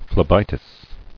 [phle·bi·tis]